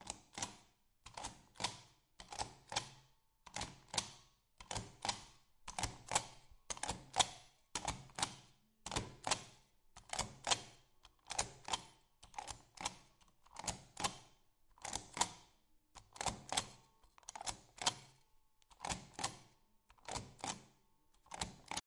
印章
描述：使用Zoom H6Stamping纸张录制声音
Tag: 办公室 OWI 冲压 文具